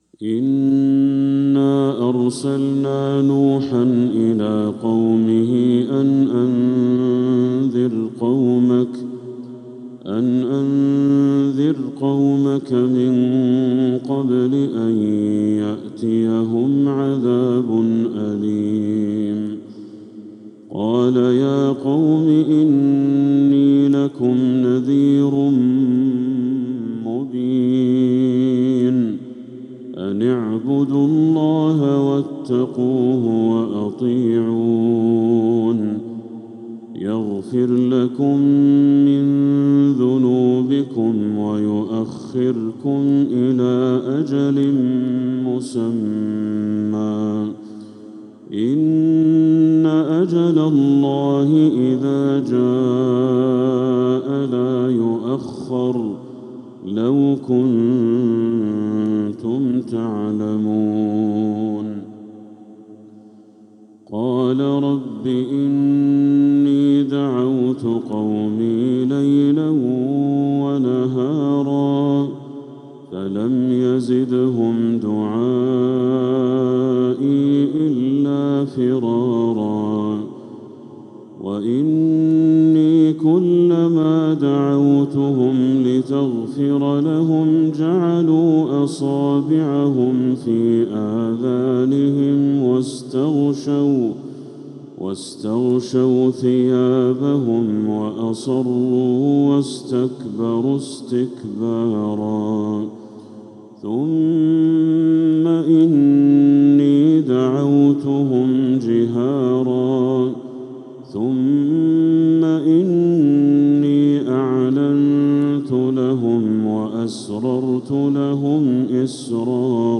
سورة نوح كاملة | محرم 1447هـ > السور المكتملة للشيخ بدر التركي من الحرم المكي 🕋 > السور المكتملة 🕋 > المزيد - تلاوات الحرمين